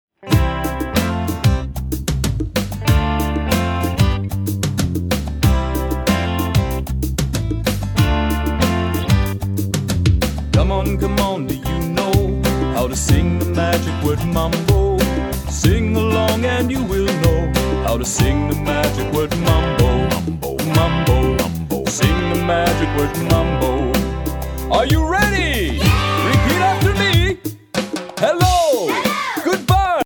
Fun, sing-a-long music for home or classroom.